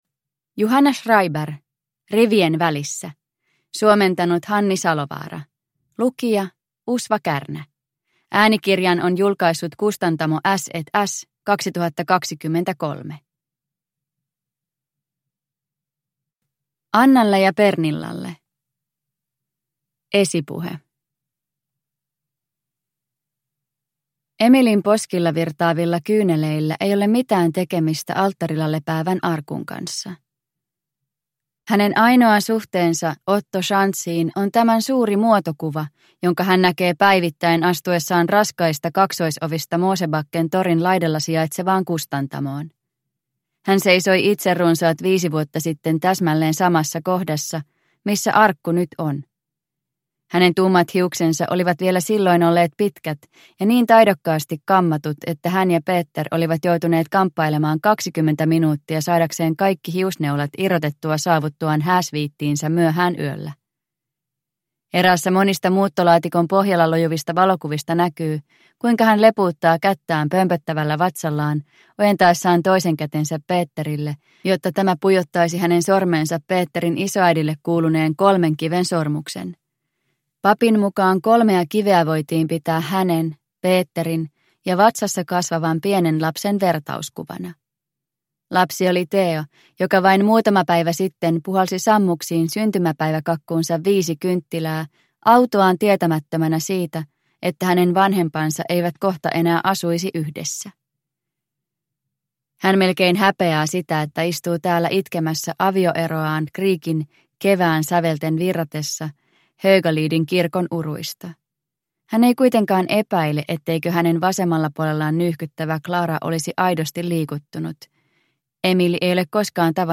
Rivien välissä – Ljudbok – Laddas ner